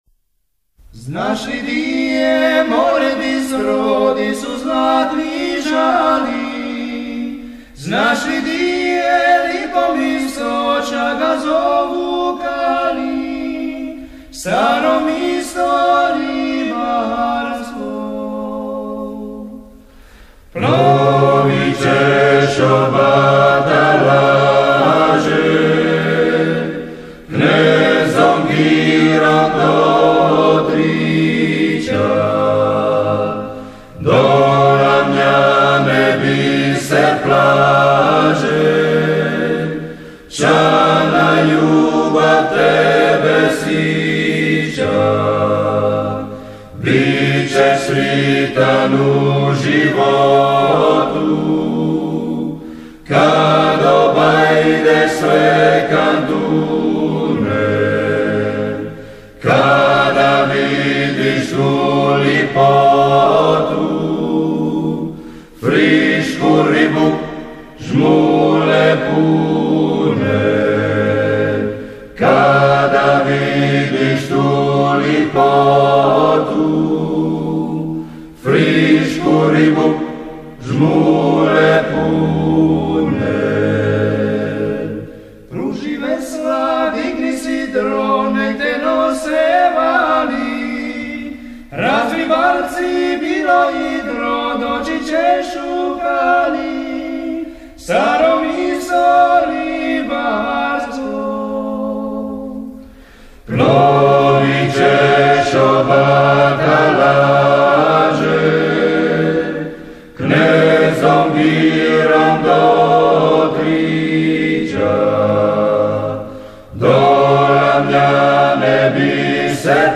I. Tenor
Bariton
I. Bas